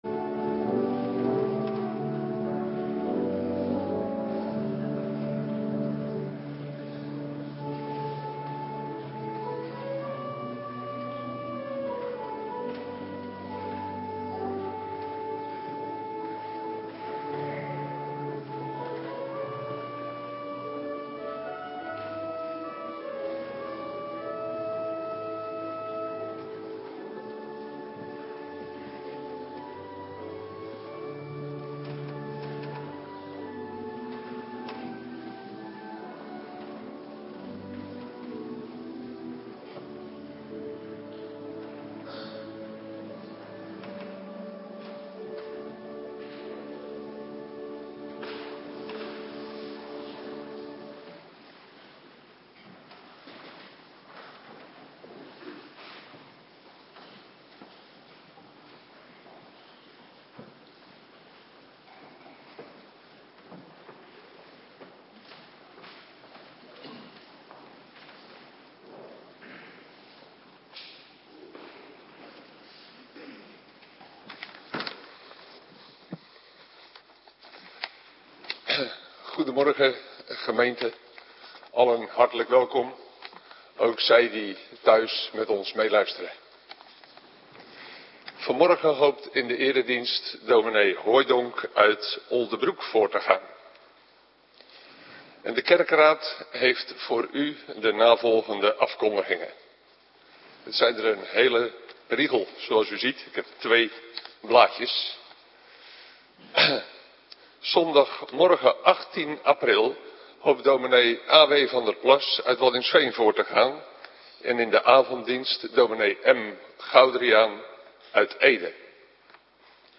Morgendienst - Cluster 2
Locatie: Hervormde Gemeente Waarder